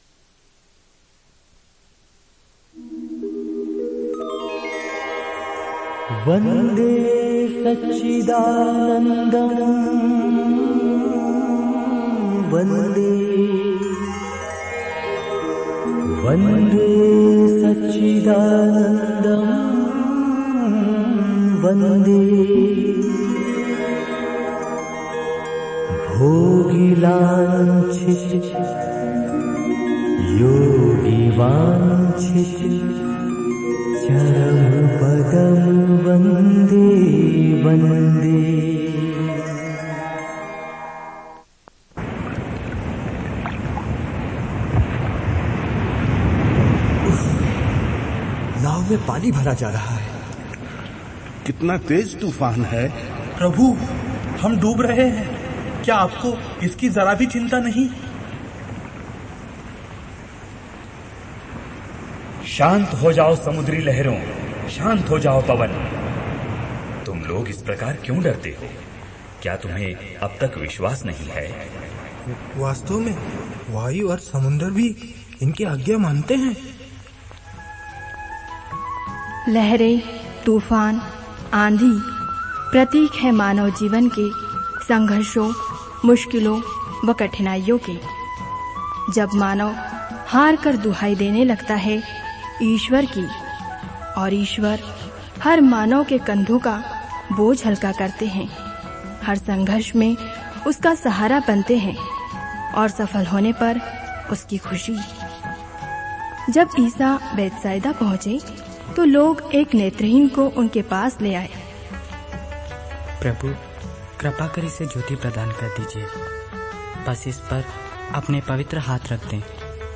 Bible Dramas